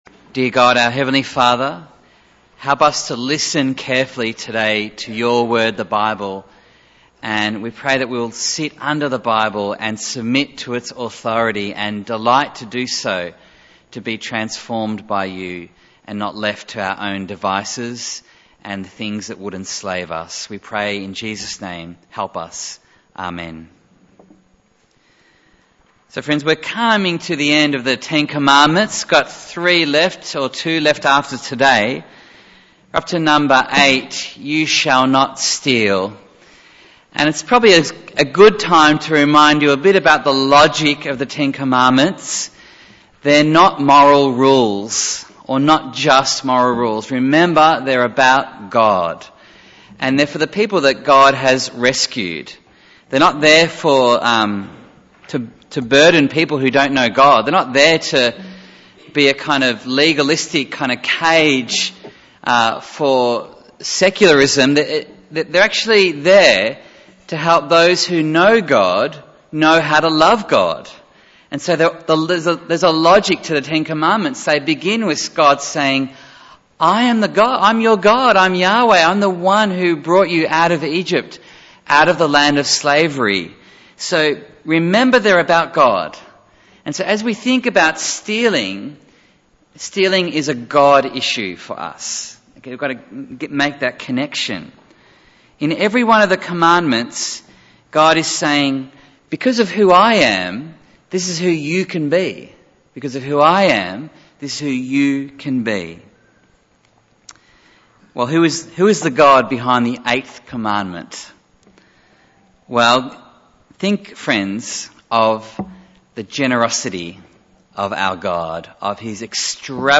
Bible Text: Titus 2:9-15 | Preacher